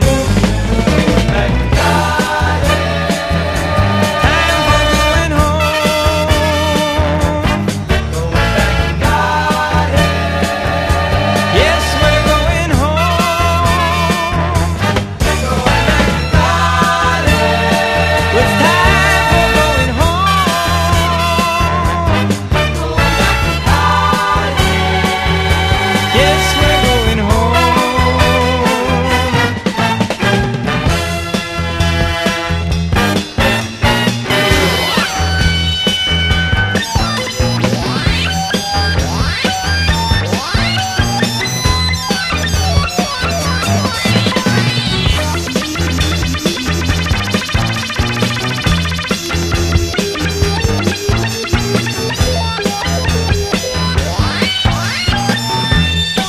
ROCK / 70'S / FUNKY ROCK / A.O.R.
合唱コーラスで幸せ一杯に包まれる
パーカッションも効いた変拍子ジャズ・ロック
ホーンやコーラス、サイケなオルガン・シンセも暴れて、羽ばたくようなグルーヴも◎